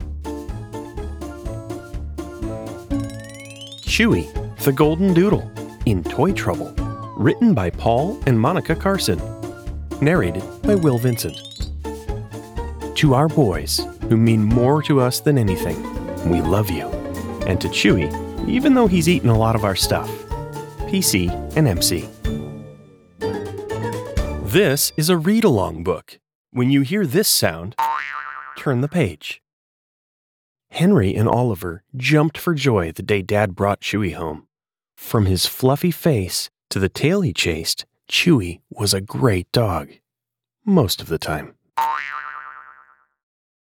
read-along audiobook